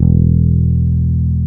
E SUS.wav